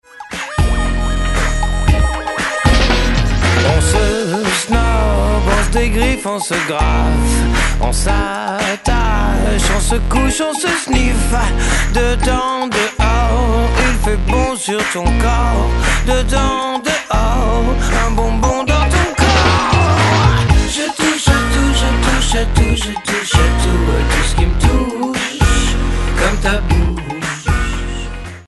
aux accents funky